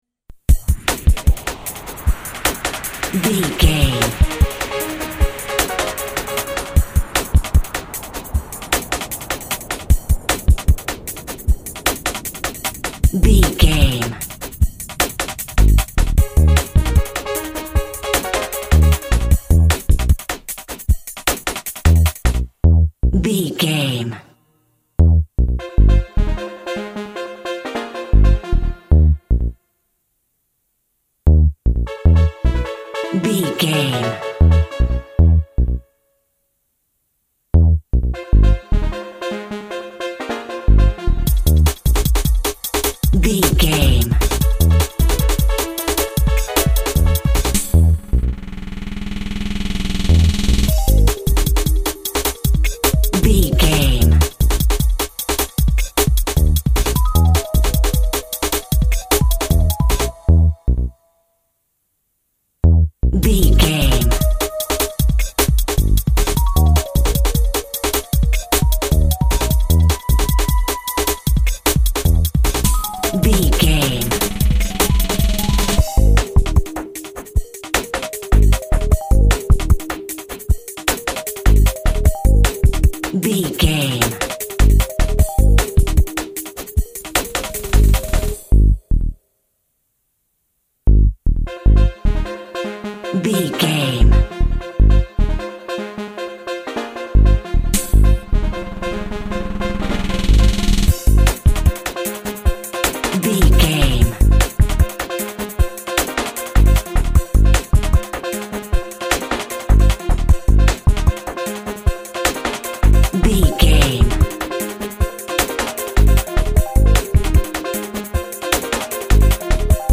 Fast paced
Aeolian/Minor
groovy
uplifting
driving
energetic
repetitive
drum machine
house
electro
techno
trance
nightclub instrumentals
synth lead
synth bass
electronic drums
Synth Pads